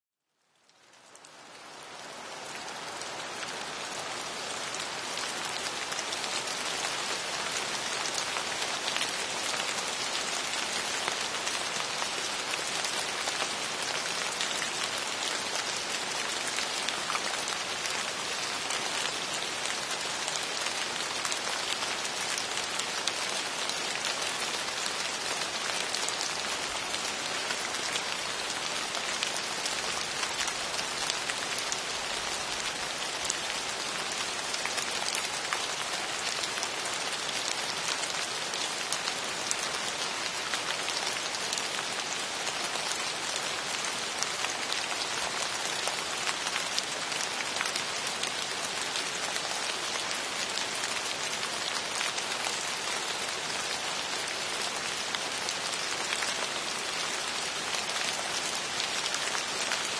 Sticky mic sounds with background sound effects free download
Sticky mic sounds with background noise to help you fall asleep.